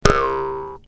clock05.ogg